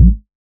Modular Tom 05.wav